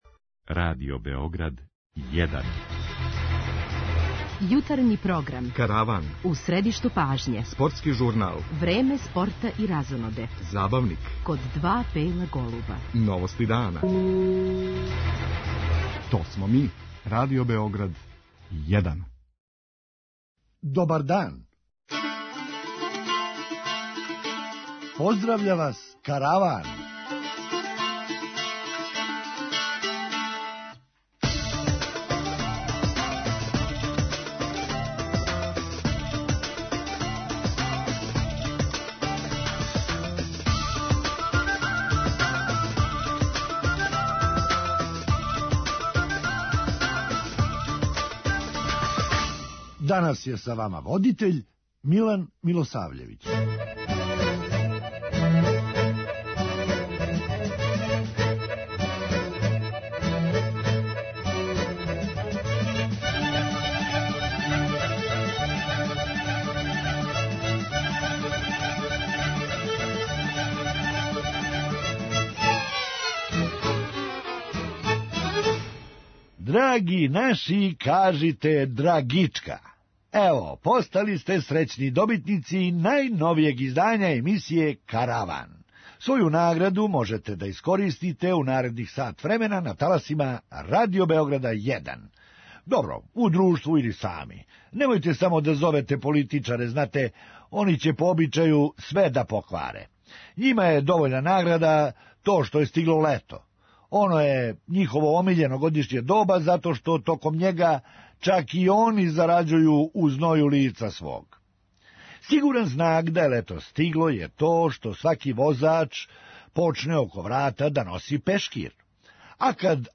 Хумористичка емисија
Човек је имао више опроштајних концерата него „Бијело дугме“. преузми : 8.93 MB Караван Autor: Забавна редакција Радио Бeограда 1 Караван се креће ка својој дестинацији већ више од 50 година, увек добро натоварен актуелним хумором и изворним народним песмама.